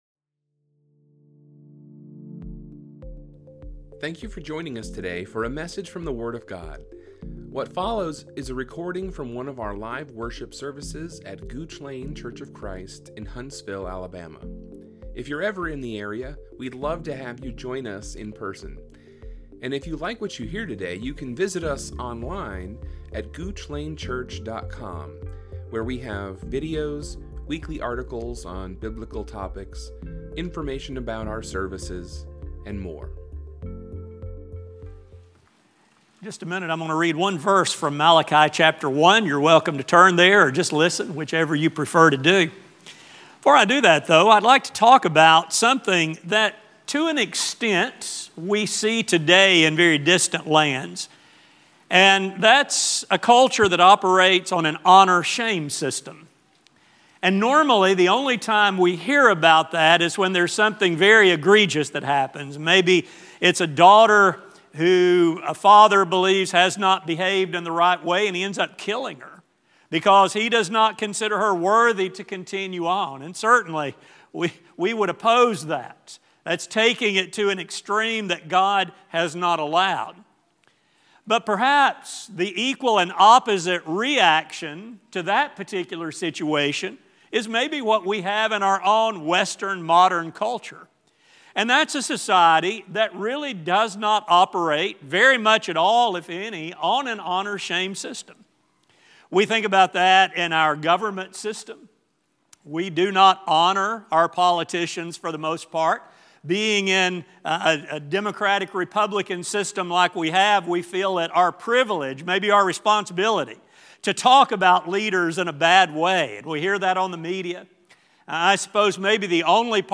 This sermon will explore this sometimes overlooked dimension of forgiveness in our relationship with God.